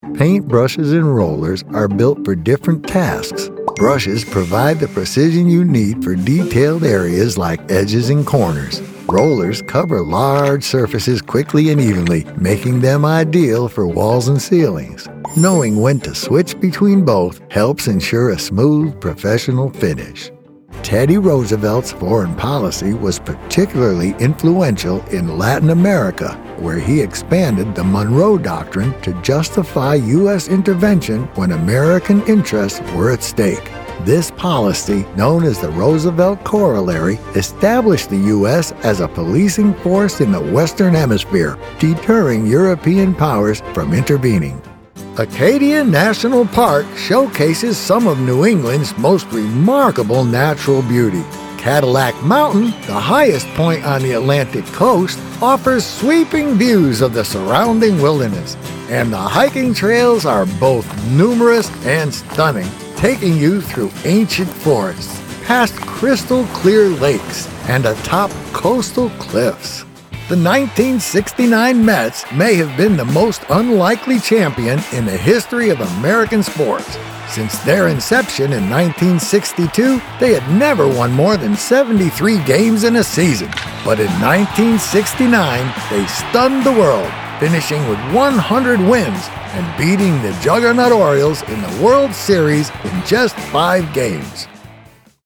Warm. Comforting. Authentic.
Narration Demo
Captivating storytelling for documentaries, audiobooks, and educational content.